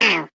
Sfx_tool_spypenguin_vo_hit_wall_10.ogg